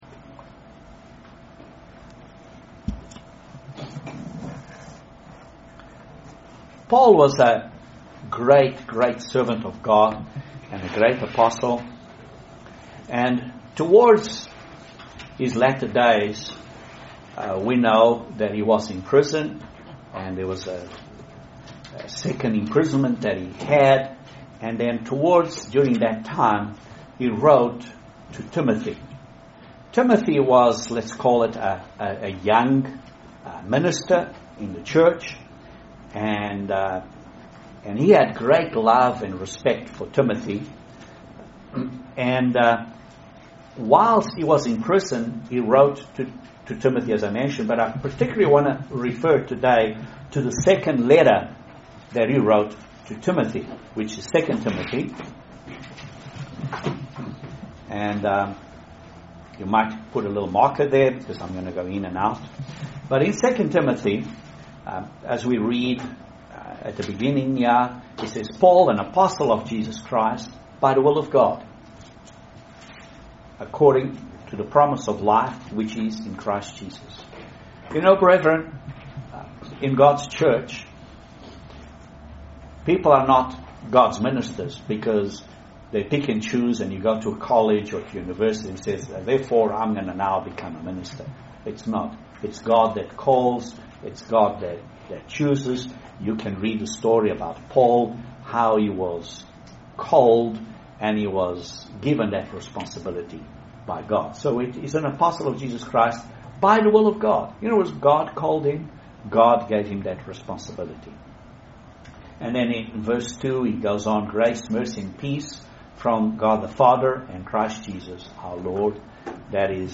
Satan controls by fear. This sermon is a study of the power of God's Holy Spirit.